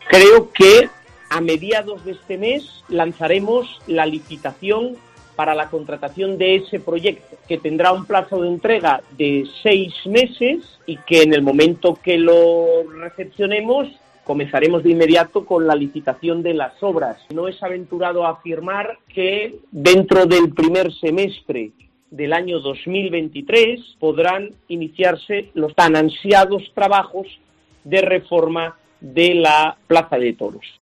El concejal de Urbanismo de Oviedo, Ignacio Cuesta, sobre la reforma de la plaza de toros